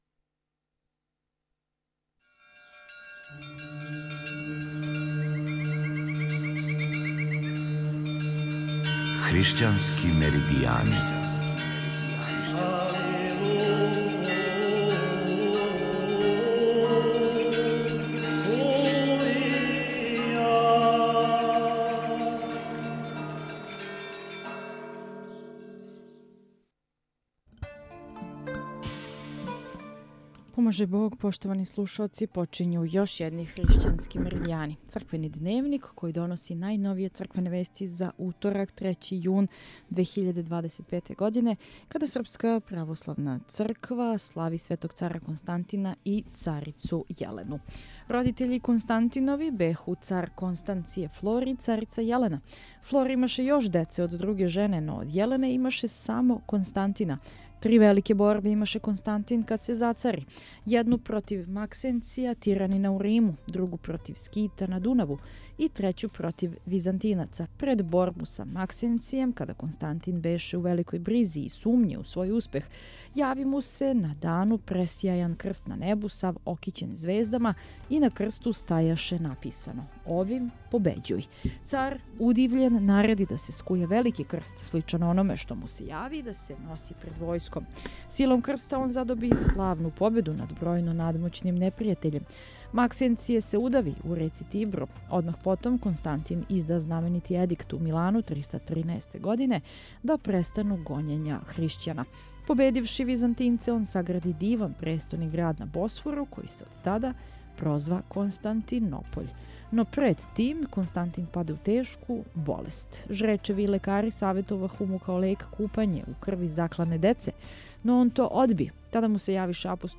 У данашњем црквеном дневнику слушаћете: